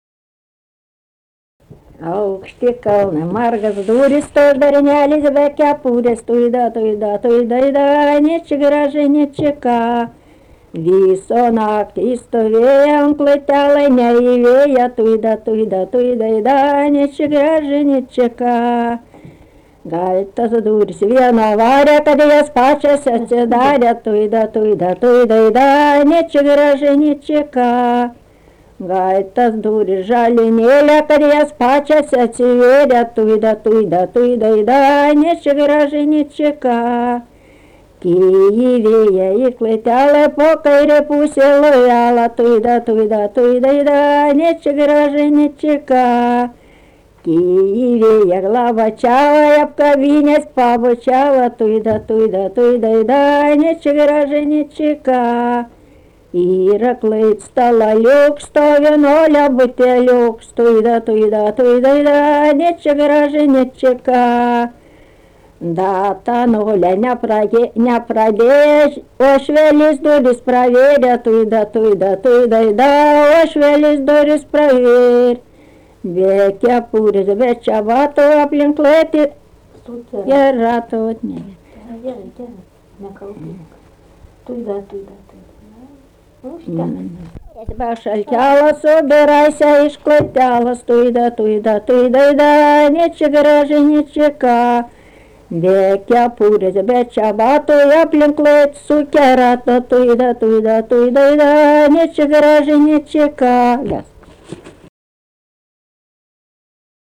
Tipas daina Erdvinė aprėptis Niurkoniai
Atlikimo pubūdis vokalinis